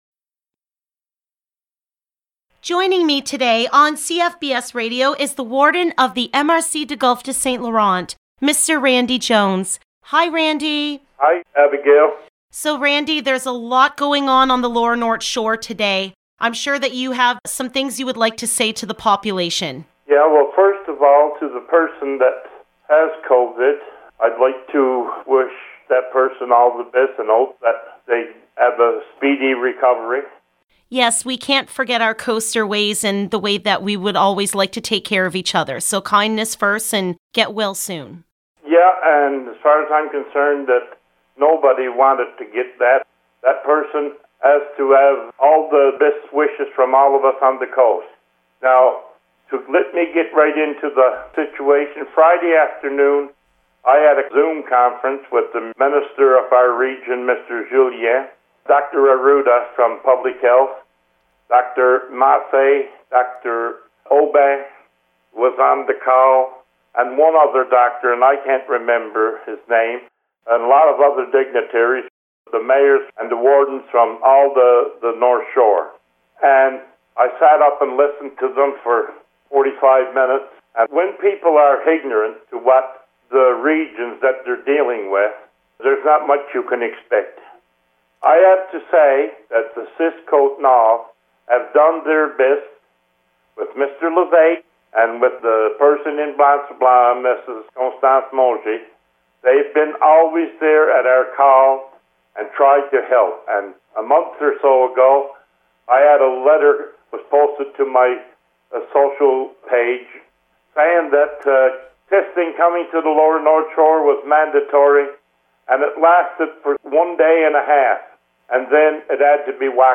Listen to the interview with Warden Randy Jones by clicking on the sound files below (click on the speaker icon next to the sound file and a media player will open up to hear the interview).